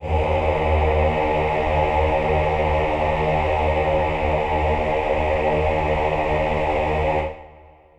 Choir Piano (Wav)
D#2.wav